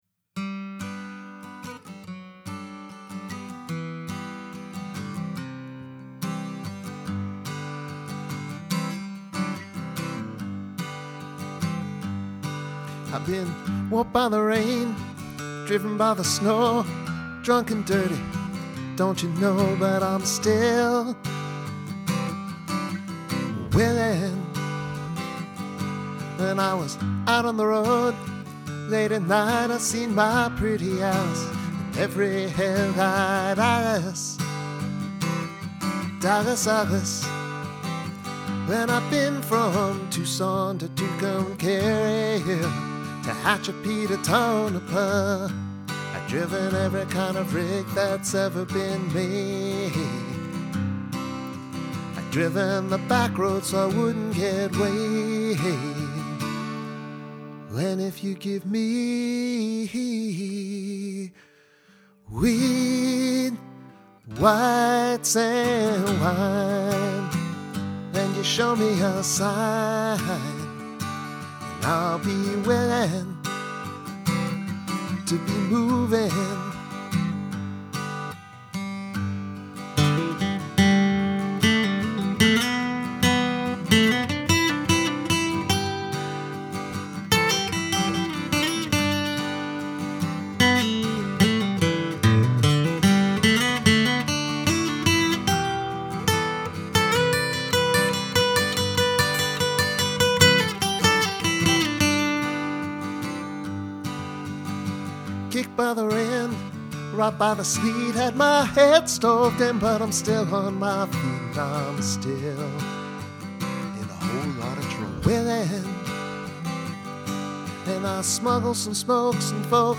Tag Archives: guitar